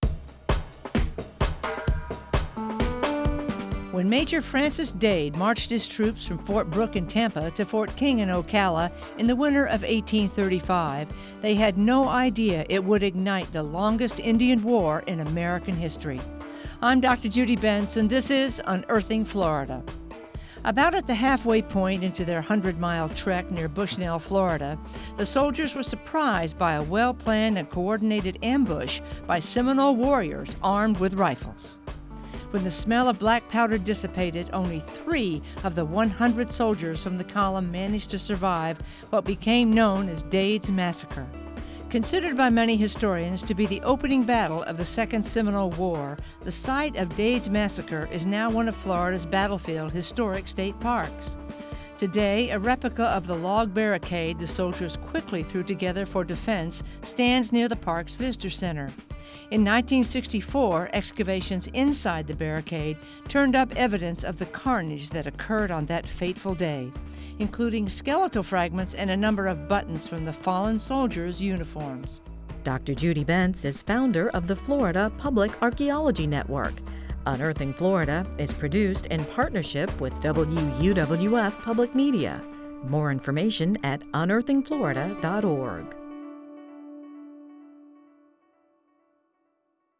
Written, narrated, and produced by the University of West Florida, the Florida Public Archaeology Network, and WUWF Public Media.